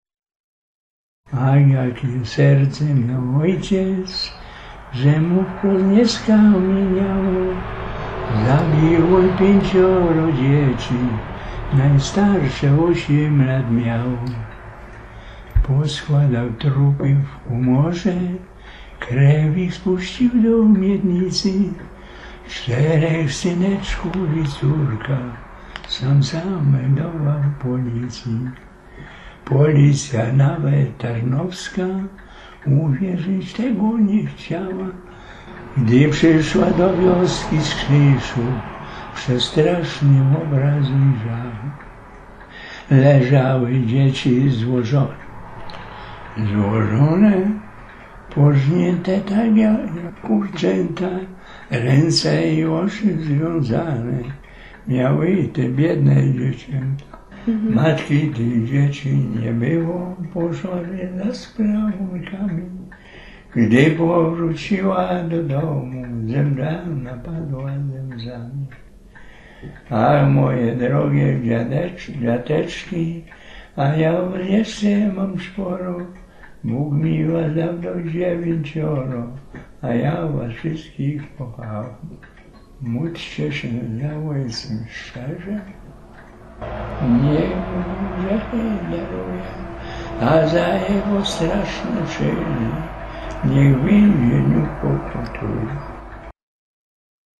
Klarnecista
(region rzeszowski)
transkrypcja zapisu terenowego